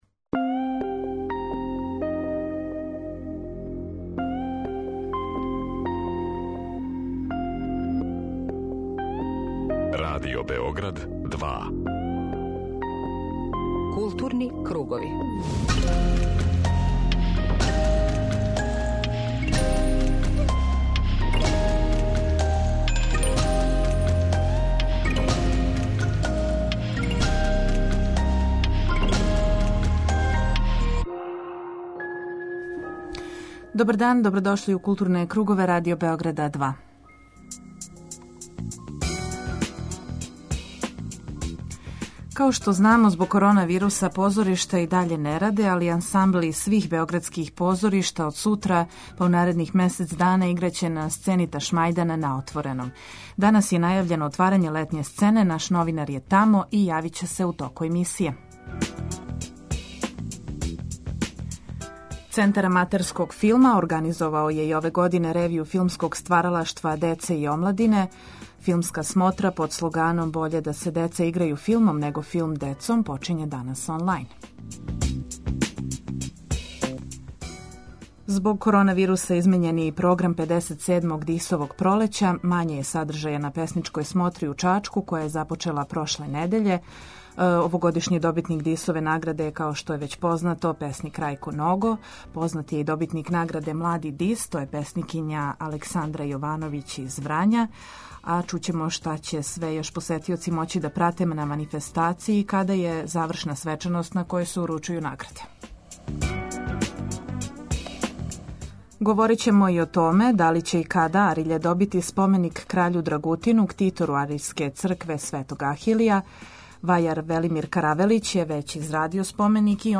Дневни магазин културе